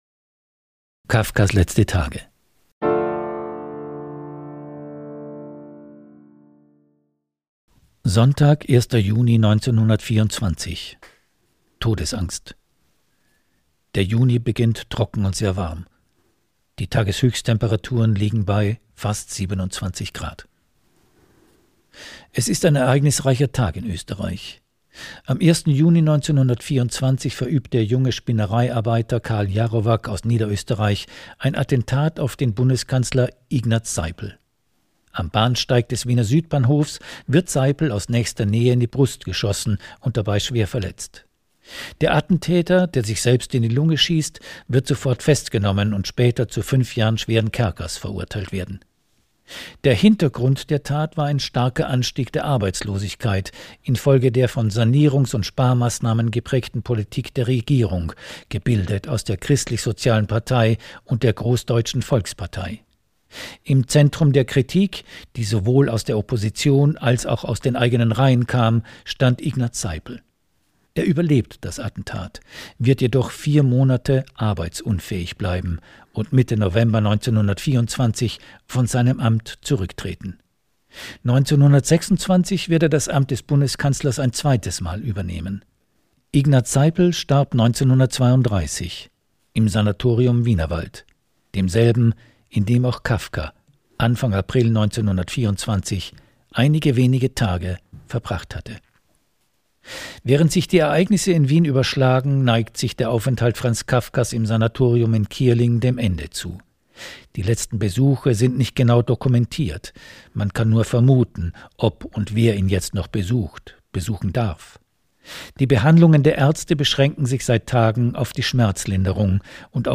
Sprecher*innen: Franz Kafka: Robert Stadlober Erzähler